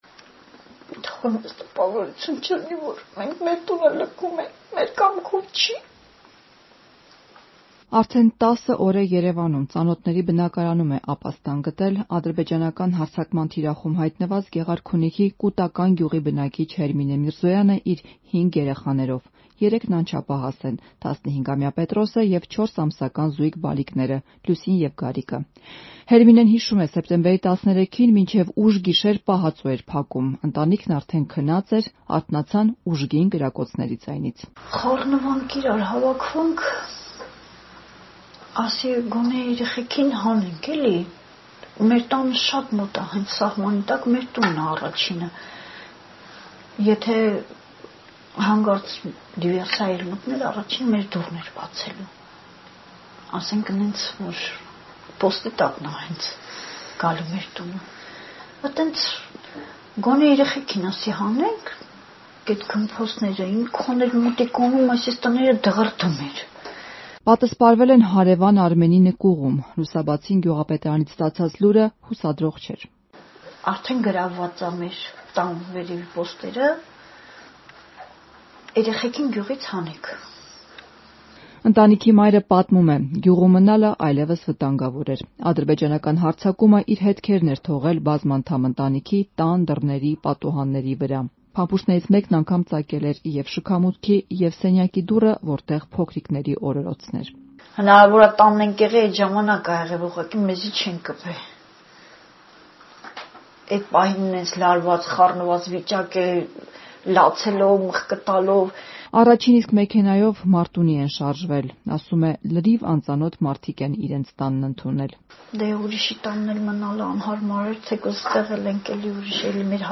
«Թող տպավորություն չլինի, թե մենք մեր տունը լքում ենք, մեր կամքով չի». Կուտականի բնակիչ
Ռեպորտաժներ